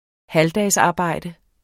Udtale [ ˈhaldas- ]